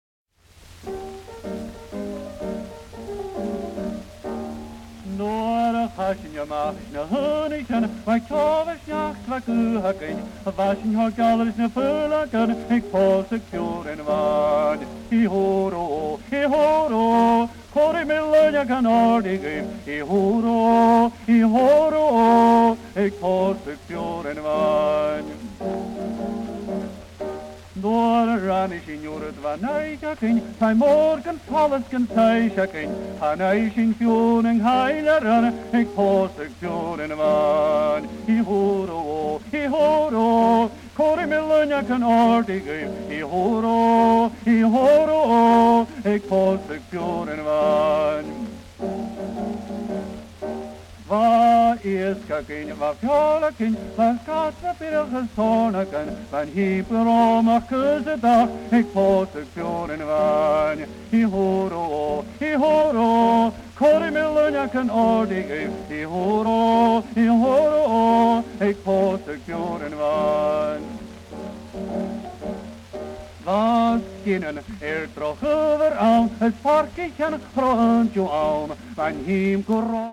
欧州で最も古い現用言語の1つ、スコティッシュ・ゲール語によるトラッド/歌謡に焦点を当てた音源集！
SP盤によるノイズも、異世界へと導いてくれるような雰囲気を漂わせていてイイですね！